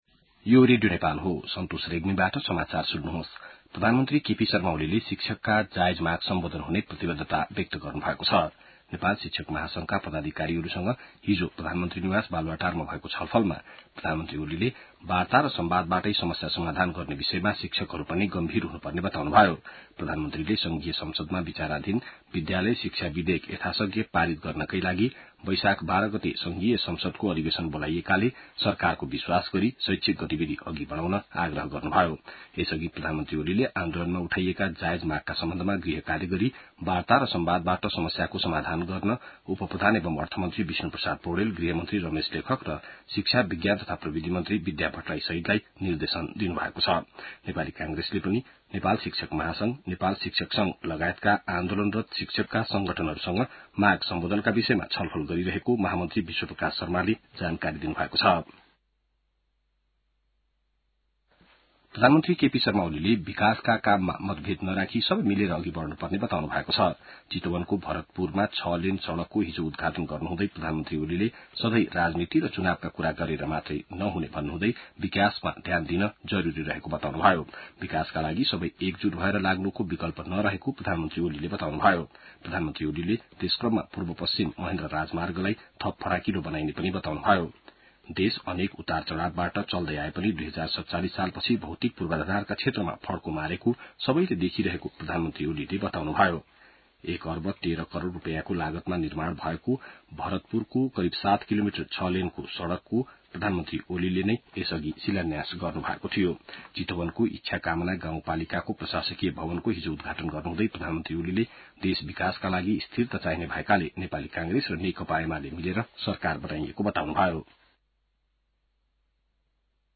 बिहान ६ बजेको नेपाली समाचार : ६ वैशाख , २०८२